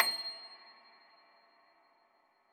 53k-pno26-C6.aif